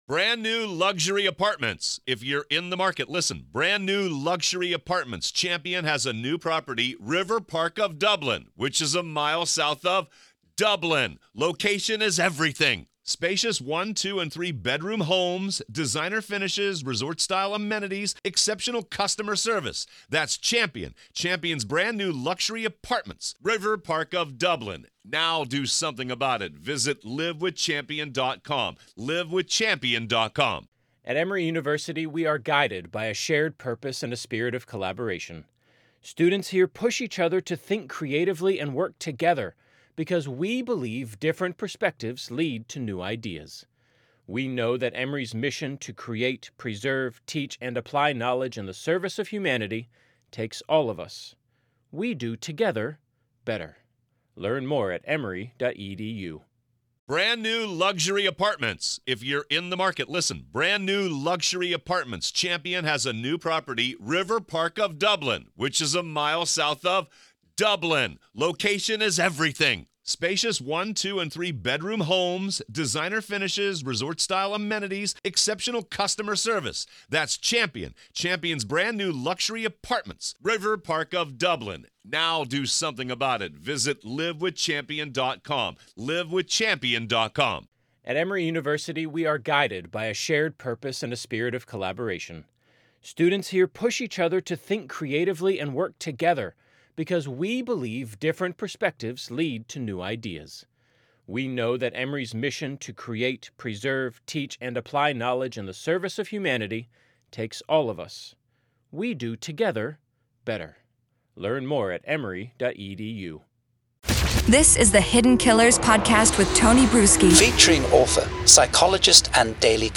The conversation explored the nuanced reality that jurors, like all individuals, are constantly influenced by their environment and unconscious biases.